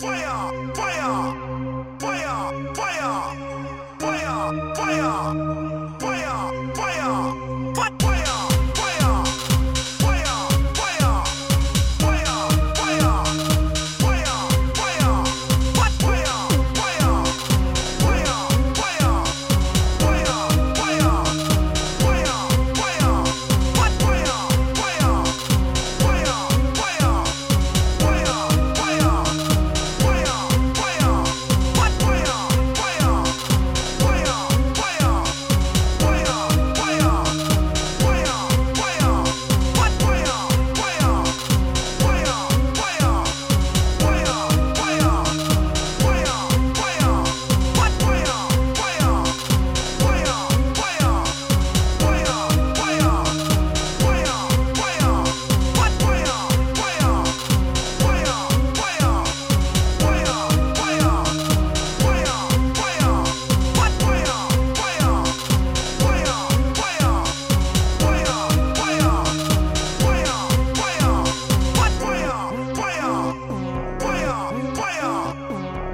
Pack from 1 to 5 [LOW quality].